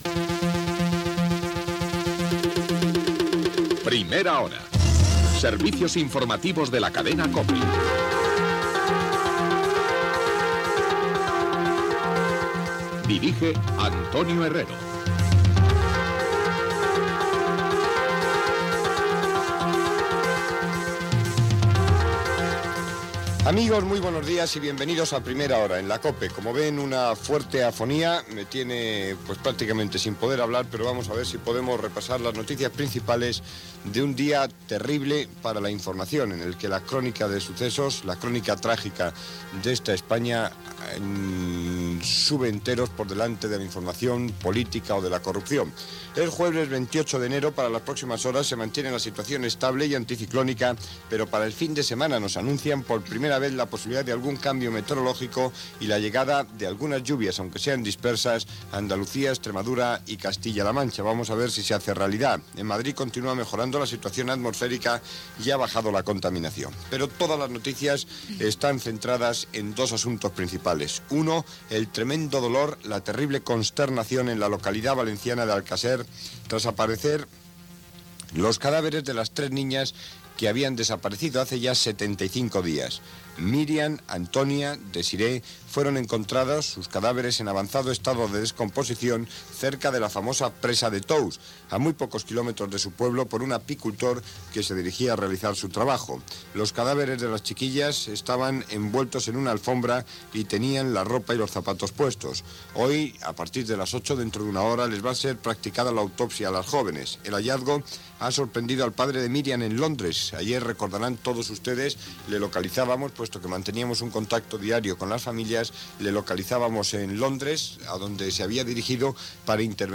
Careta, data, el temps, informació del crim d'Alcàsser, indicatiu, opinió, ampliació del crim d'Alcàsser i entrevista al tinent d'alcaldia de la localitat
Info-entreteniment